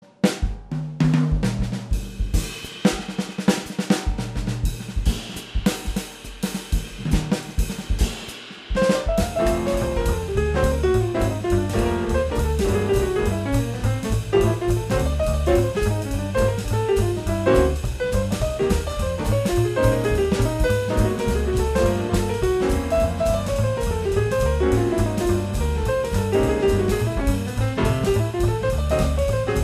piano
batteria, percussioni
registrato ai Millennium Studio di Roma